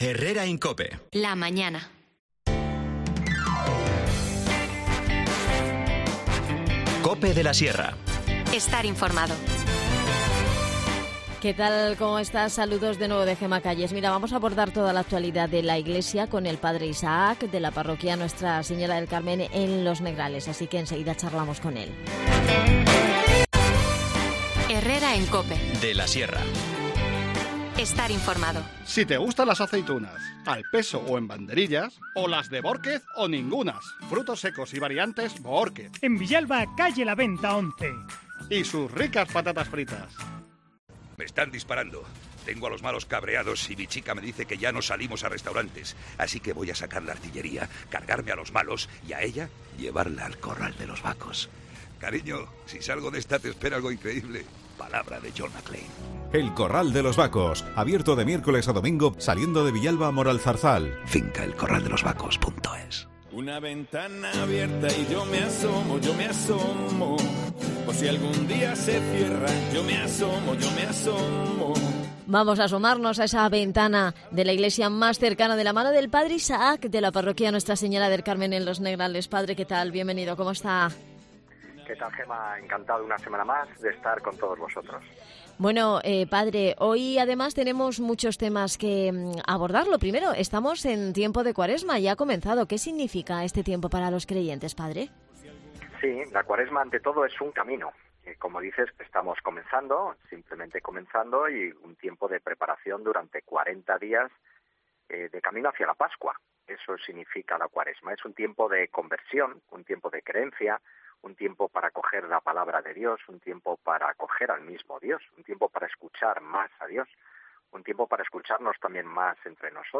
INFORMACIÓN LOCAL
Las desconexiones locales son espacios de 10 minutos de duración que se emiten en COPE, de lunes a viernes.